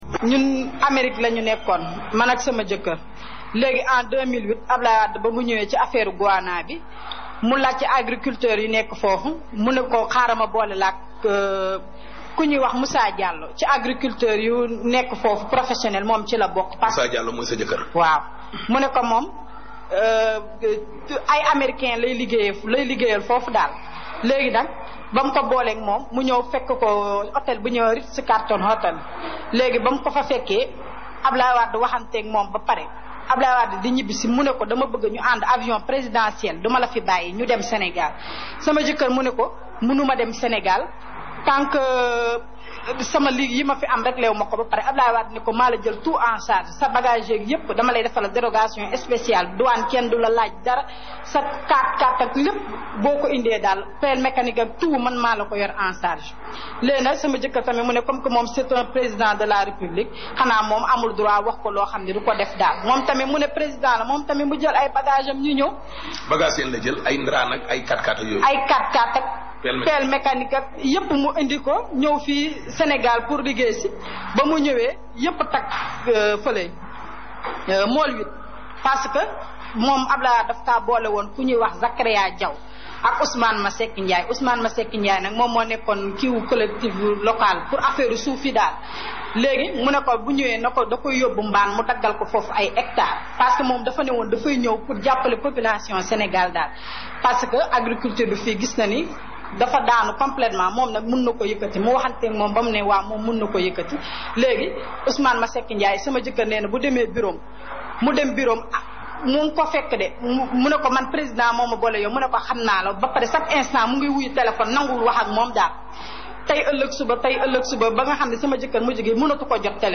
ECOUTEZ. La femme d'un technicien agricole raconte comment Wade les a bernés